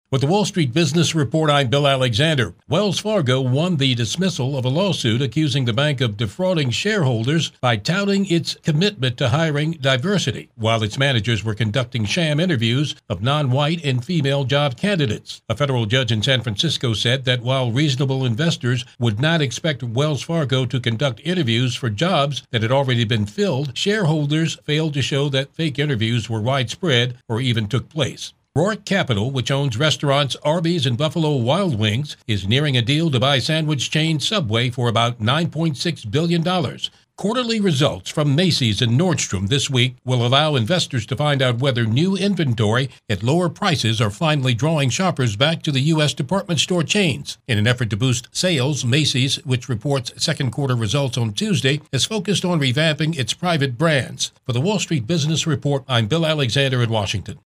Up-to-date business news
AM-WALL-STREET-BUSINESS-TUESDAY-8-22-23.mp3